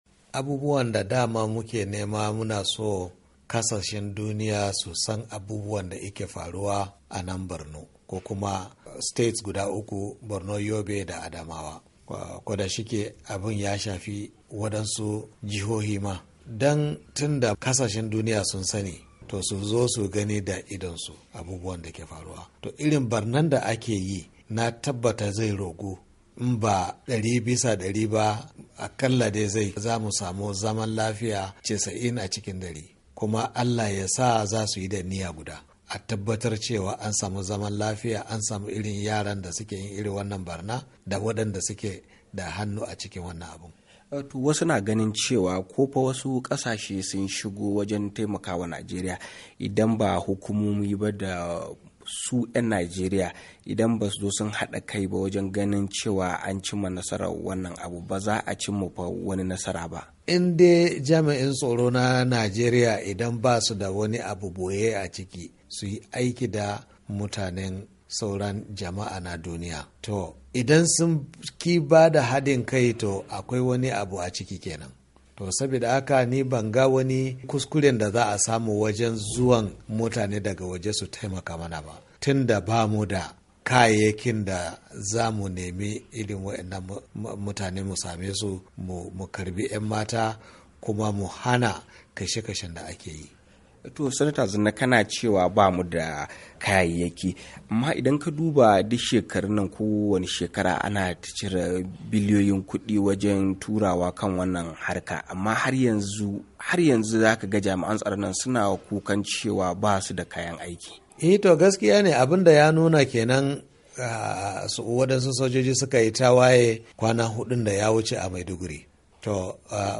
Hira da Senata Ahmed Zanna - 3'41"